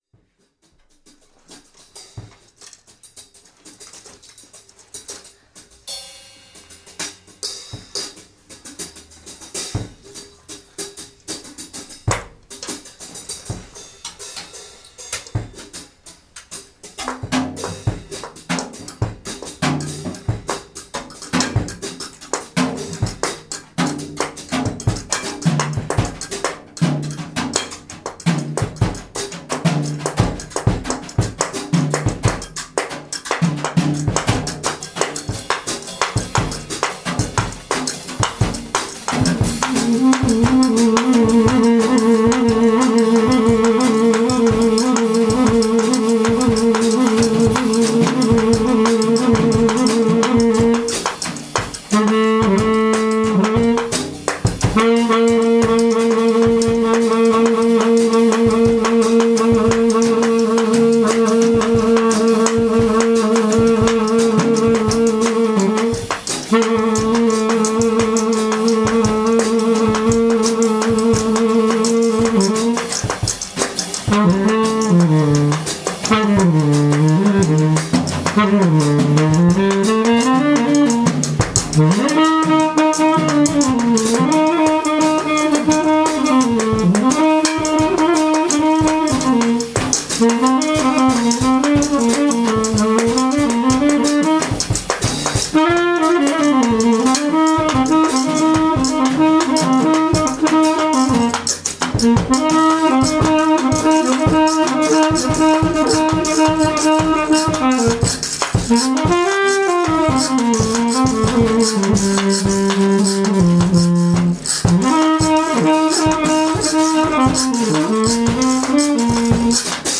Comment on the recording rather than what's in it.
Recorded live Stereo (Olympus portable recorder)